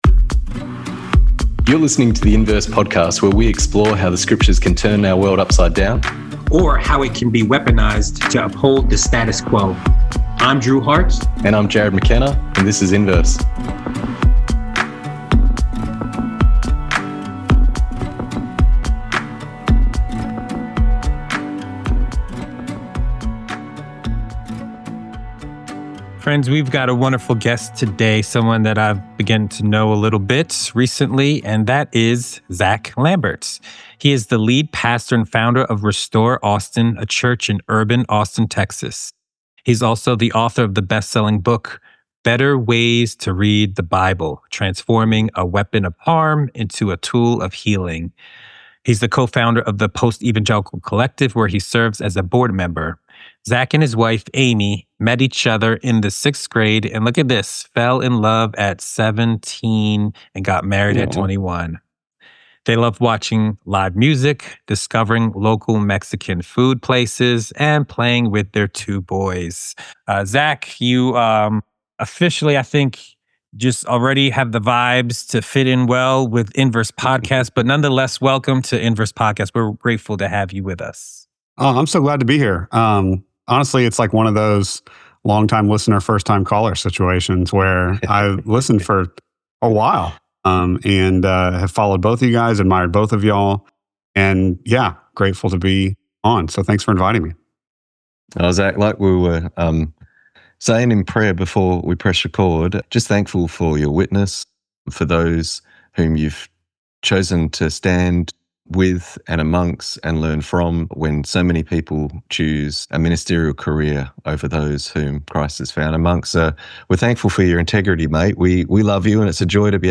In today's conversation